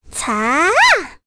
Requina-Vox_Casting4.wav